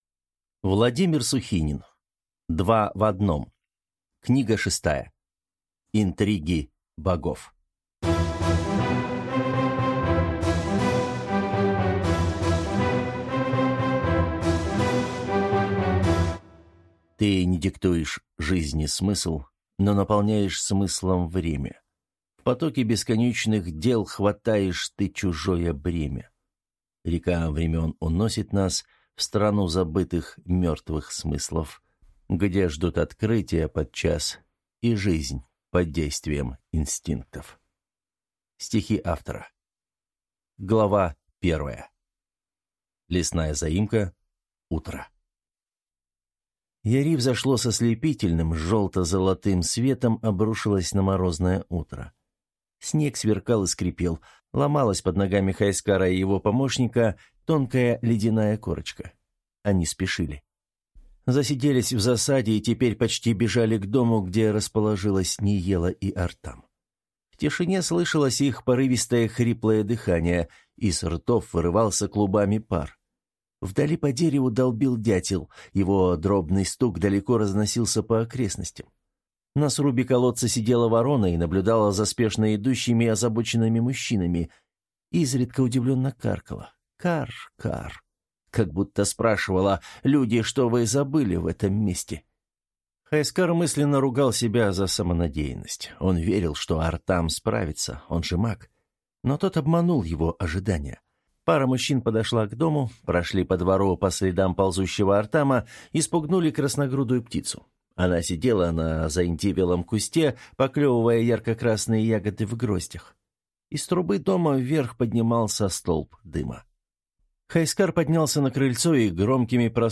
Аудиокнига Интриги Богов | Библиотека аудиокниг